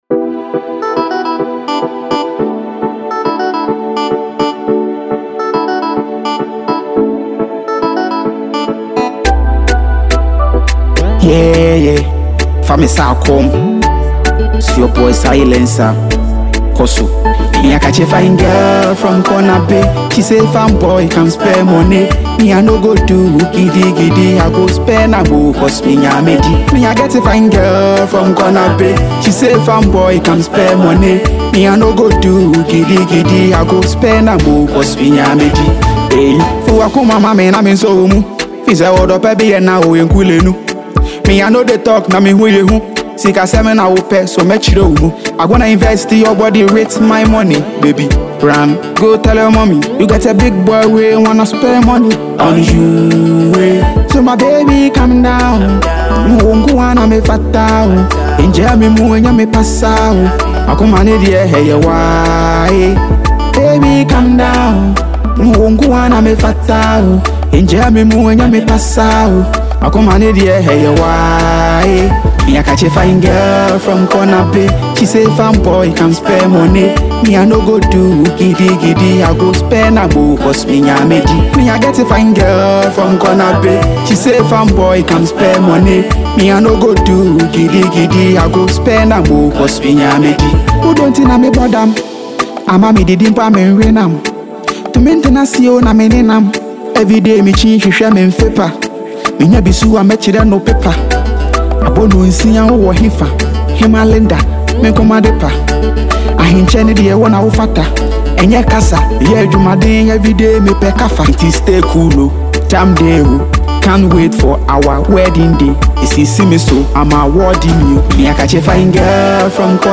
Home » Ghana Song
Afrobeat
blends melodic Afrobeat vibes with a laid-back groove
With its catchy hook and relatable theme